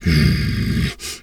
Animal_Impersonations
wolf_2_growl_01.wav